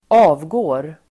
Uttal: [²'a:vgå:r]